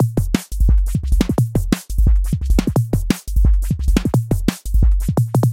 丛林管鼓DNB
标签： 174 bpm Drum And Bass Loops Drum Loops 954.32 KB wav Key : Unknown
声道立体声